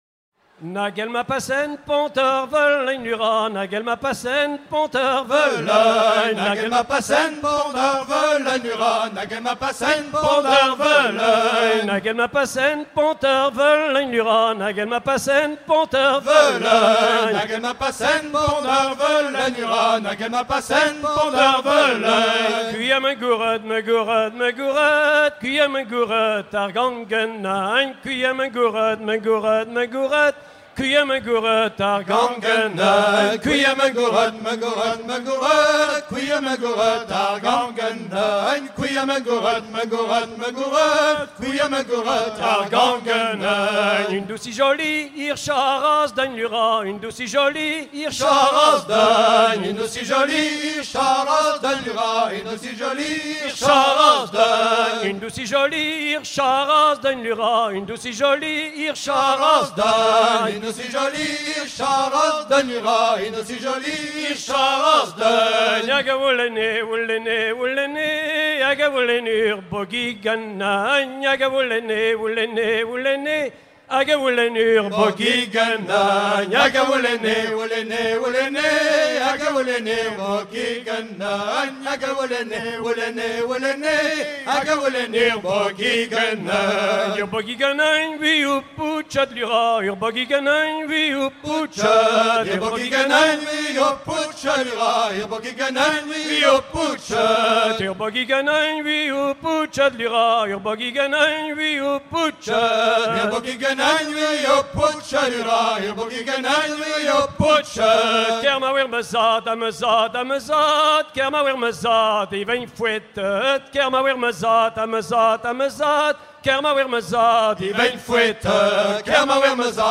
Deux Kas a-barh très connus proposés au choix du groupe : Ar plah a-Rianteg et Nag él ma pasen pont er velin